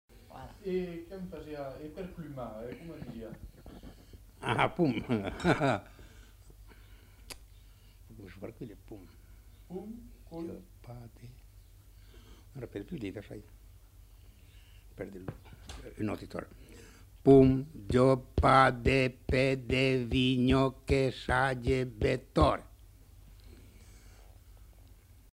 Lieu : Saint-Rémy-de-Gurson
Genre : forme brève
Effectif : 1
Type de voix : voix d'homme
Production du son : récité
Classification : formulette enfantine